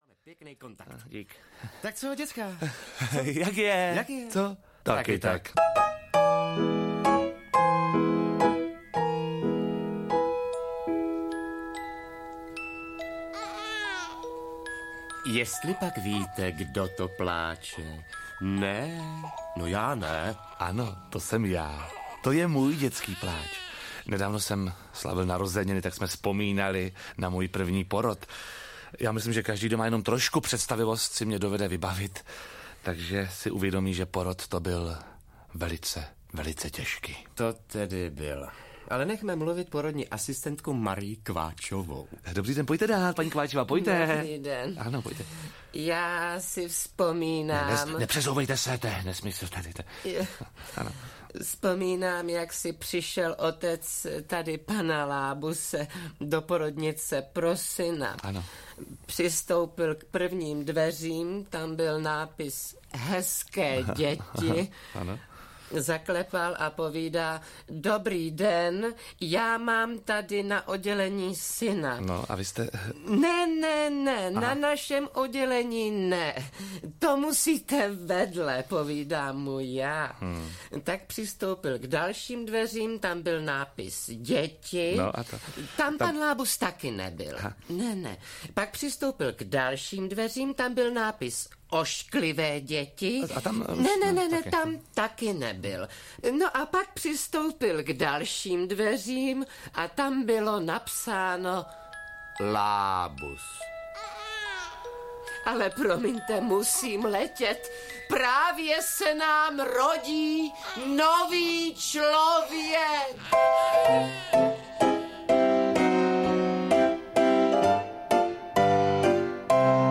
Ukázka z knihy
• InterpretOldřich Kaiser, Jiří Lábus
nekonecna-deska-audiokniha